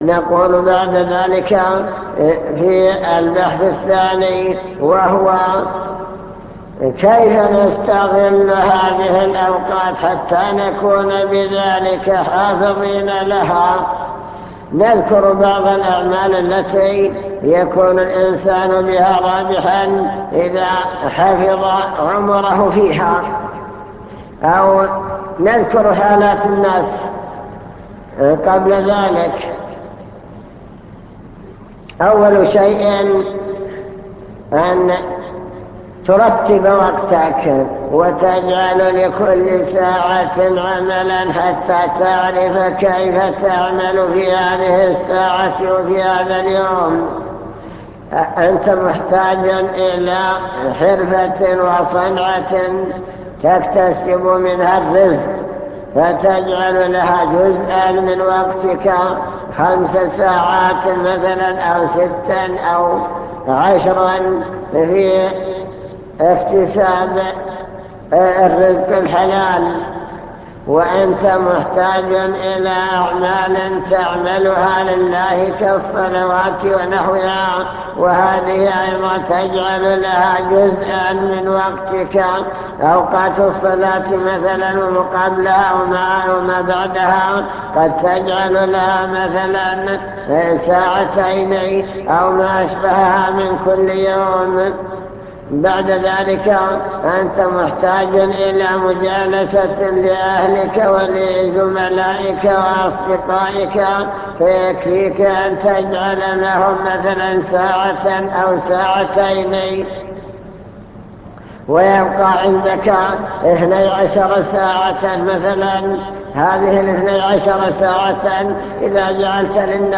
المكتبة الصوتية  تسجيلات - محاضرات ودروس  محاضرة بعنوان الشباب والفراغ